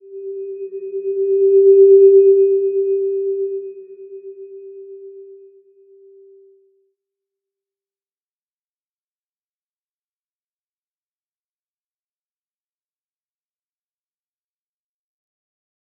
Slow-Distant-Chime-G4-p.wav